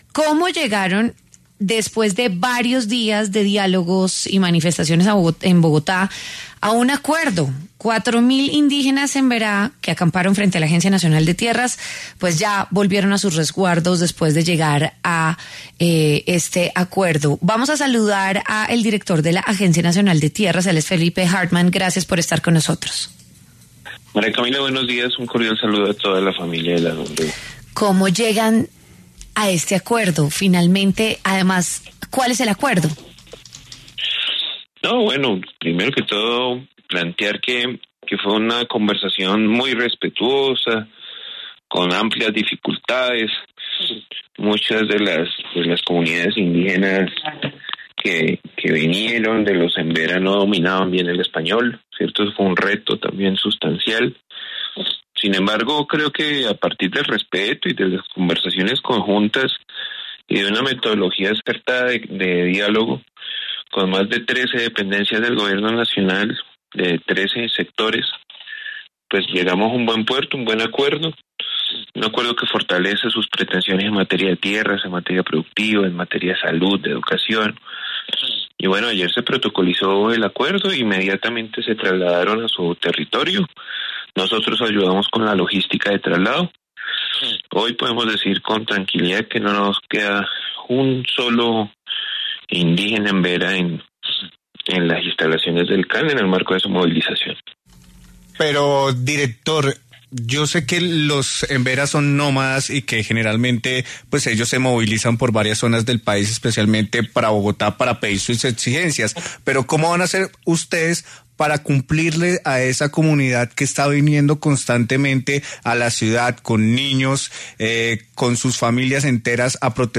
Felipe Harman, director de la Agencia Nacional de Tierras (ANT) , habló con W Fin de Semana acerca del acuerdo que permitió a los más de 4.000 indígenas emberá volver a sus resguardos.